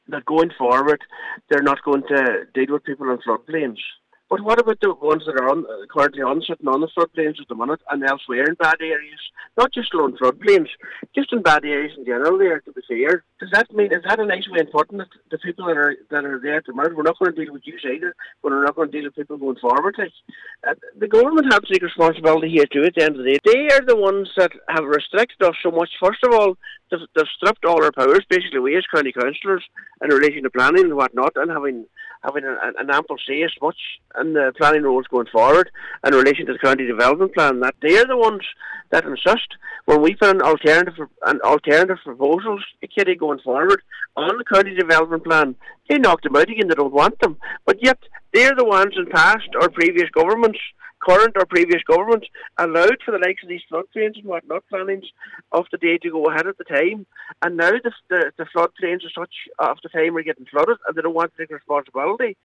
Now, Cllr Michael McClafferty says Councillors have essentially had their powers stripped, weakening their positions on planning matters: